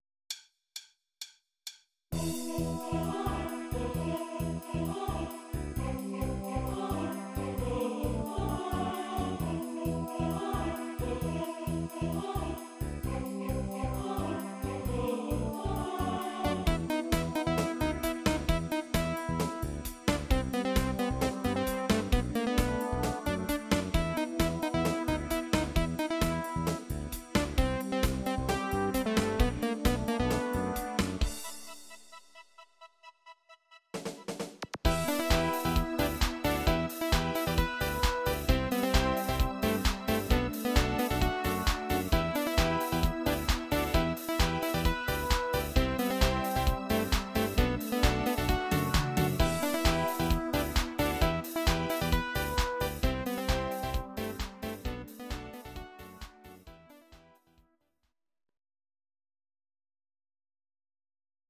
Demo's zijn eigen opnames van onze digitale arrangementen.